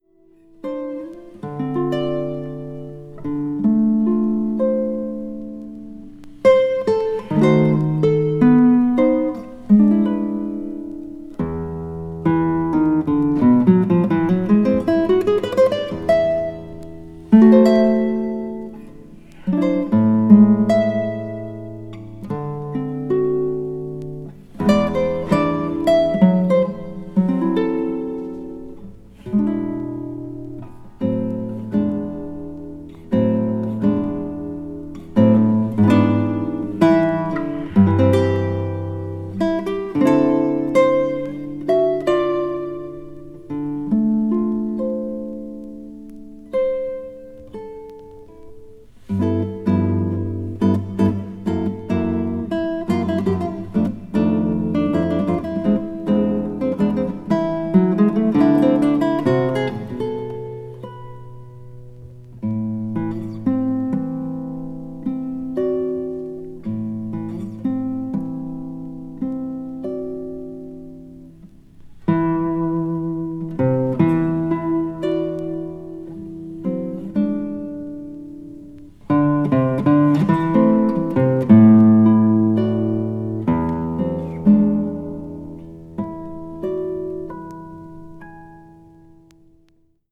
media : EX/EX(わずかにチリノイズが入る箇所あり)
まったく刺のない穏やかなサウンドがとても心地良く音場に浮遊します。